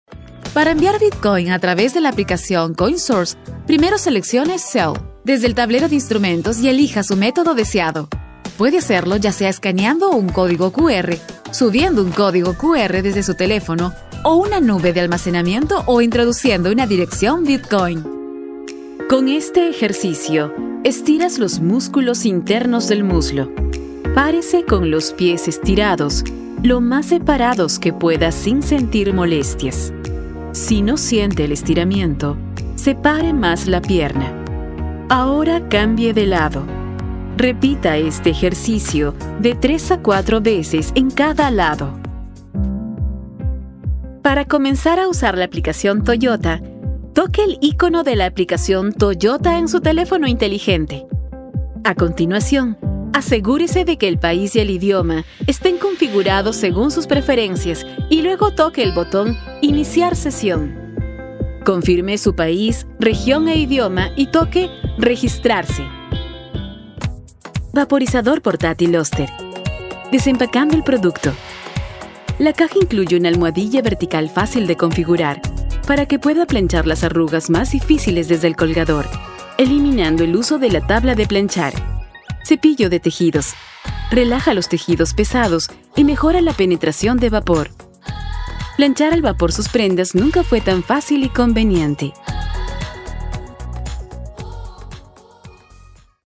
Latin American Spanish female voice over artist from Peru. If you need a warm and elegant, but still natural and conversational voice
Sprechprobe: Sonstiges (Muttersprache):